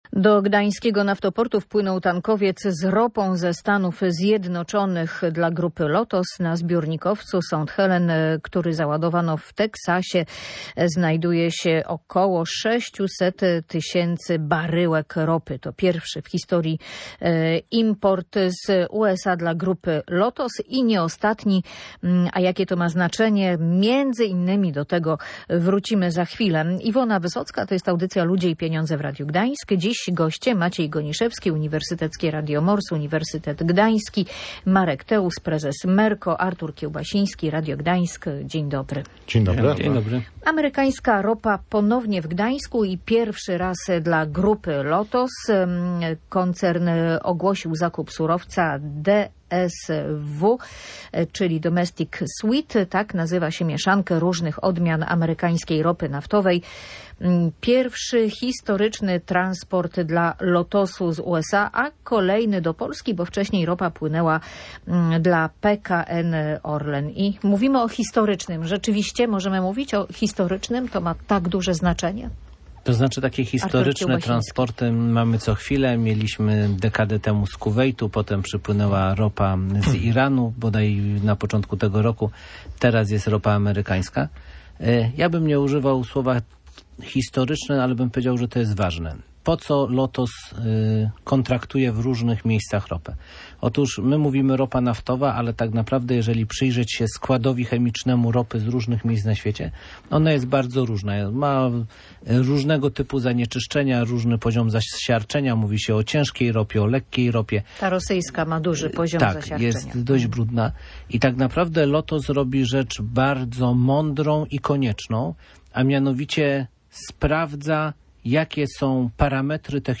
Znaczenie tego transportu było jednym z tematów audycji Ludzie i Pieniądze.